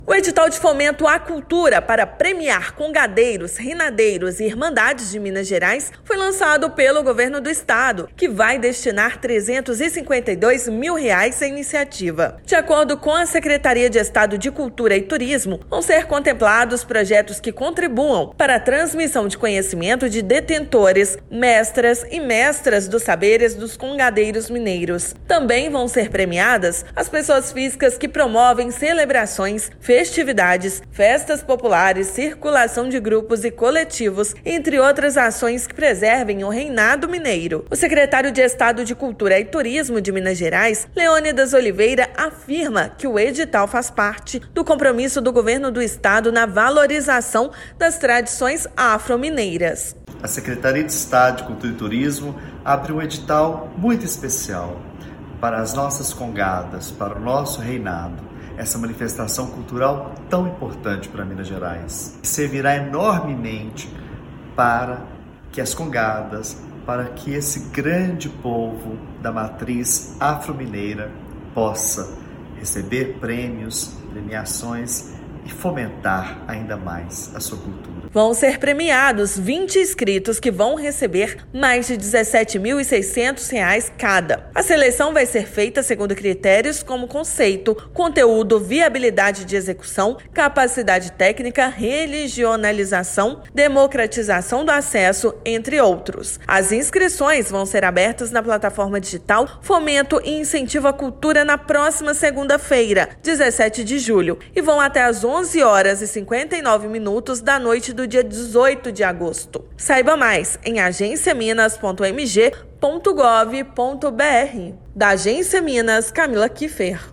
O Governo de Minas Gerais, por meio da Secretaria de Estado de Cultura e Turismo (Secult-MG), lança o edital de fomento à cultura para premiar congadeiros, reinadeiros e irmandades de Minas. A iniciativa vai destinar R$ 352.133,45. Ouça matéria de rádio.